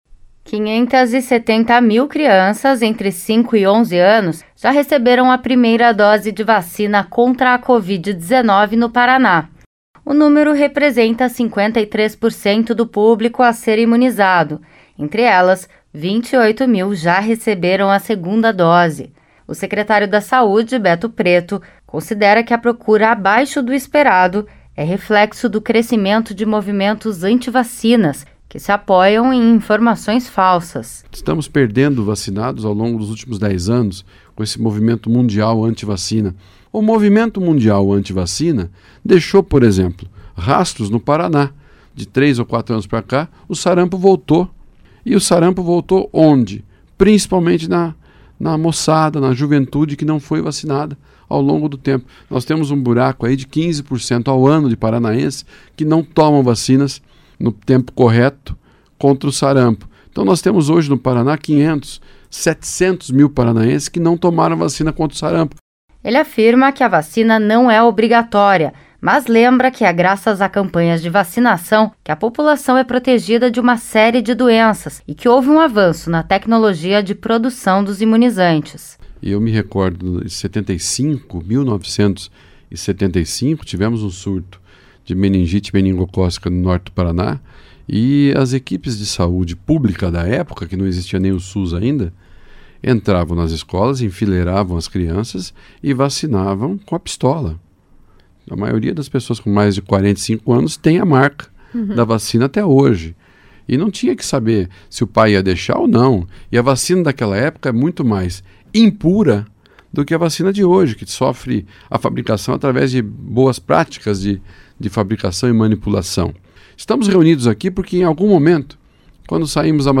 Em entrevista à Rede Aerp de Notícias, Beto Preto falou sobre os avanços na vacinação infantil contra a covid-19. Ele ainda avaliou a segurança do imunizante e a importância da proteção coletiva.